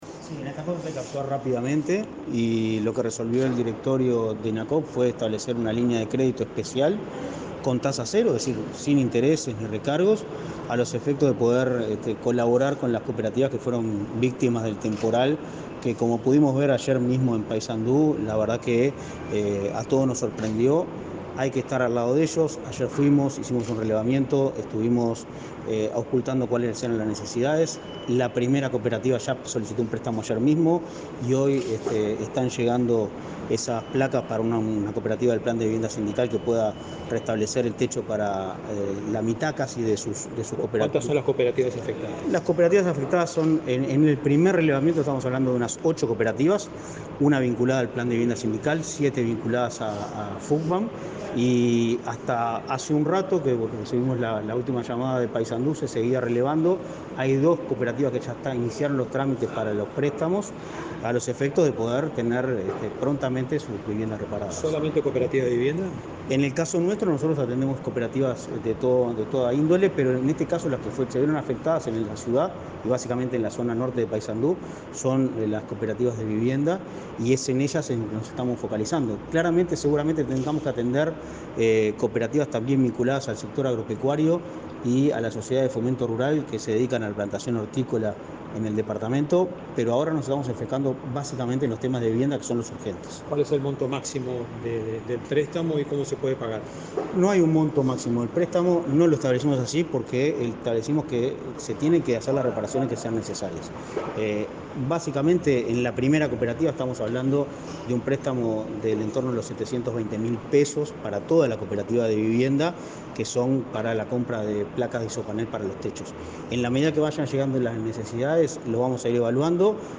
Declaraciones del presidente de Inacoop, Martín Fernández